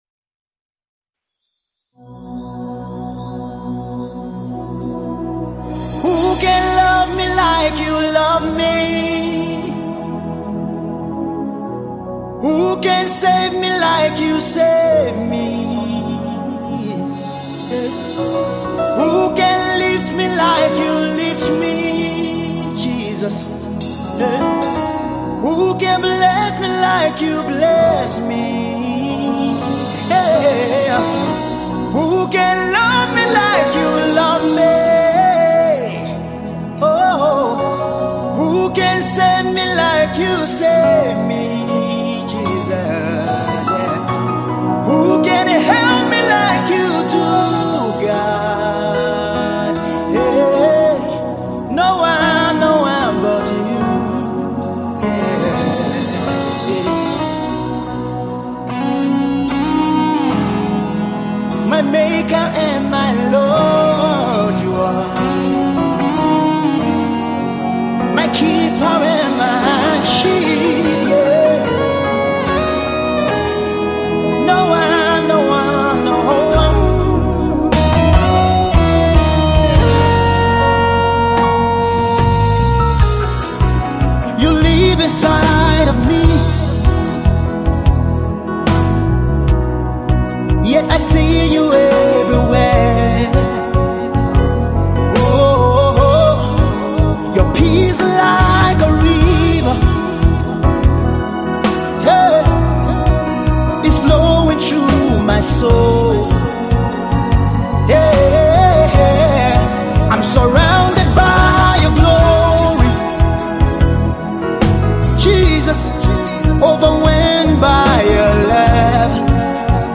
heavenly worship tune